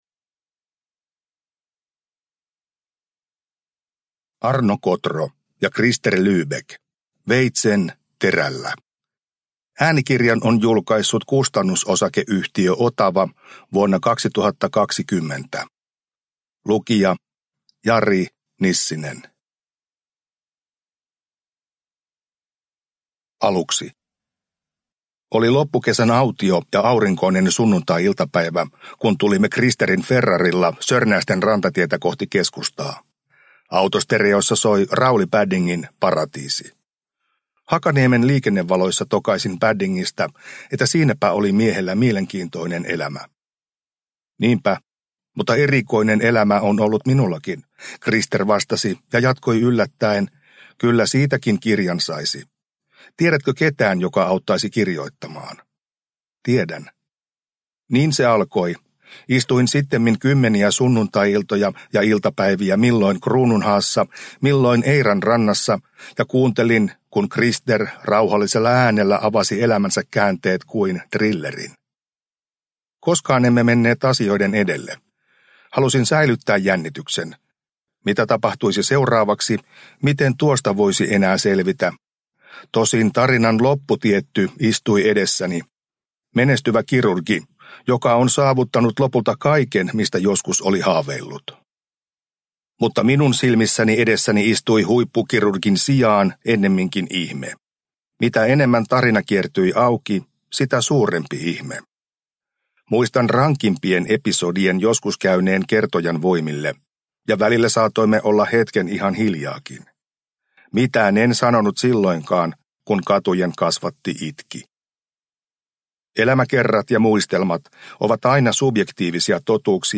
Veitsen terällä – Ljudbok – Laddas ner